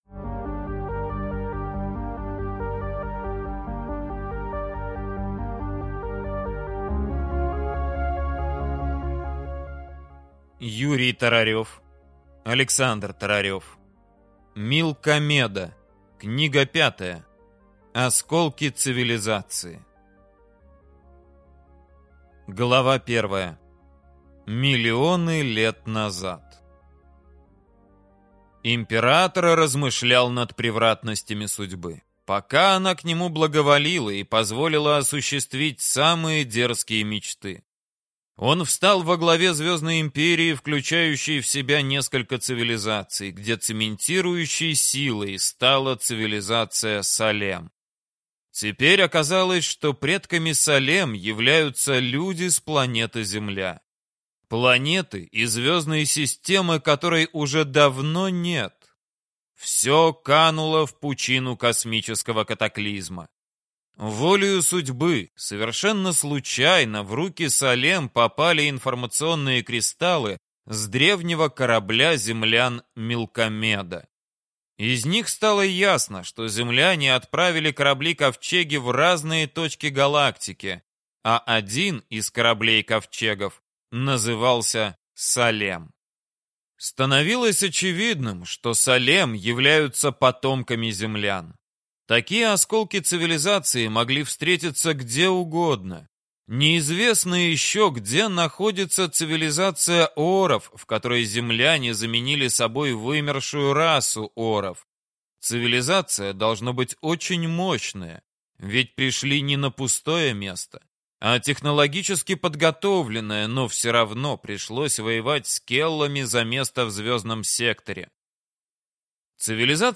Аудиокнига Милкомеда. Книга 5. Осколки цивилизации | Библиотека аудиокниг